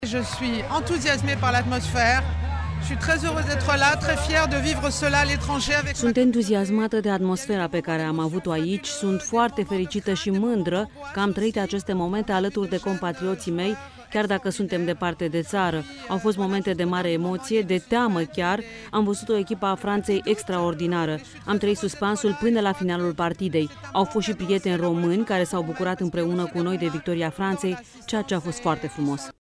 La eveniment a participat şi Ambasadoarea Franţei la Bucureşti, Michelle Ramis, care a declarat, la finalul partidei, pentru Radio România Actualităţi: